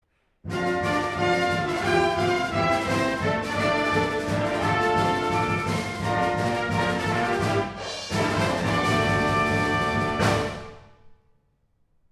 ファンファーレ